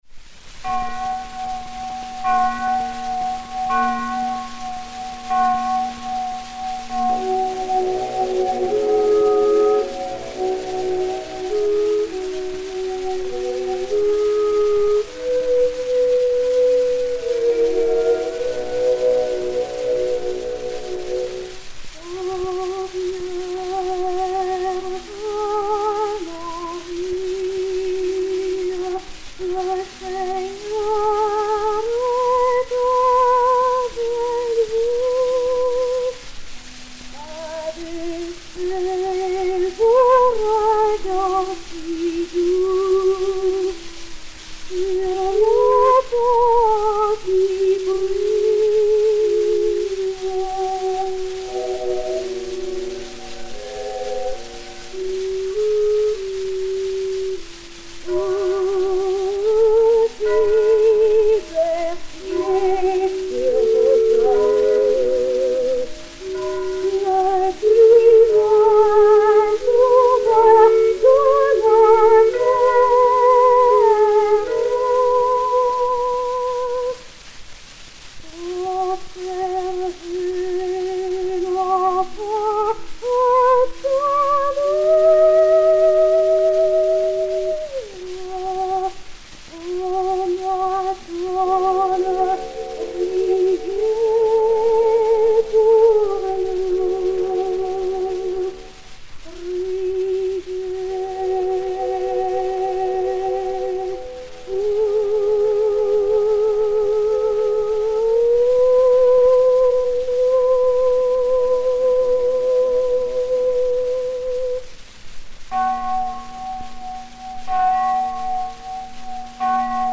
Jeanne Marié de l'Isle (Mignon) et Orchestre
Zonophone X 83077, mat. 6014o, enr. à Paris en 1906